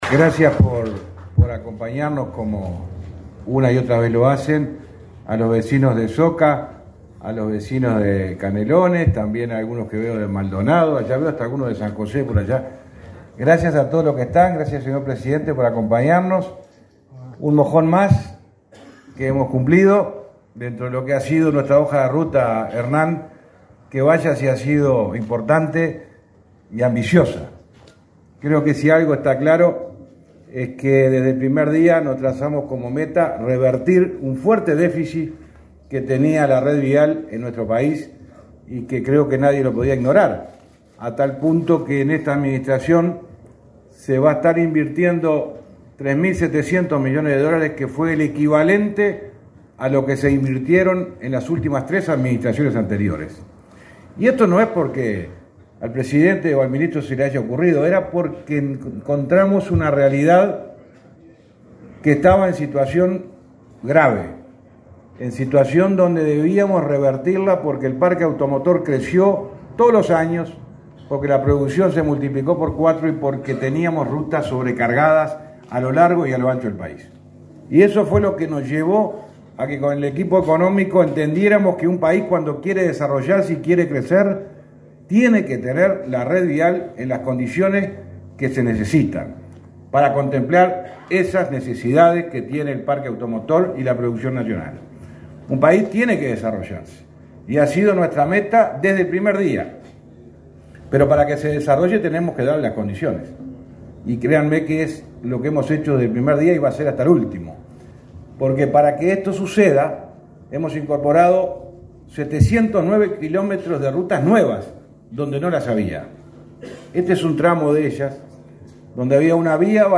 Palabras del ministro de Transporte y Obras Públicas, José Luis Falero
En la inauguración de obras de duplicación de vía en ruta 8, por una inversión superior a los 55 millones de dólares, este 17 de setiembre, se expresó